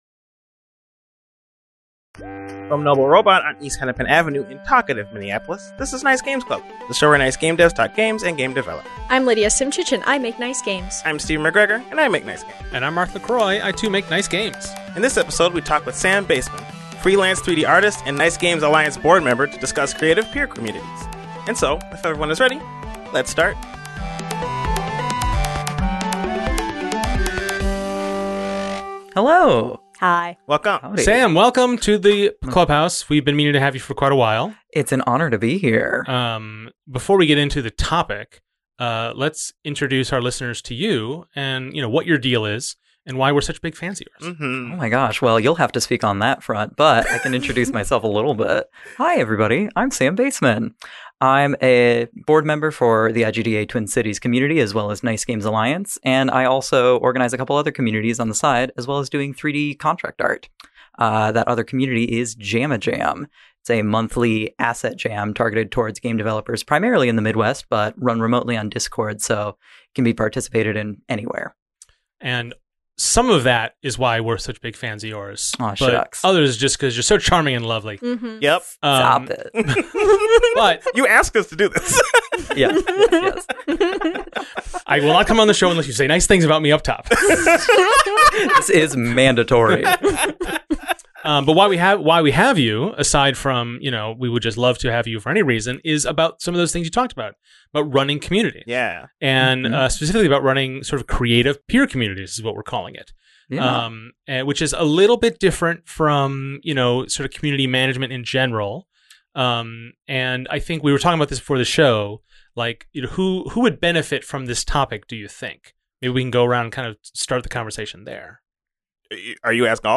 The podcast where nice gamedevs talk gaming and game development.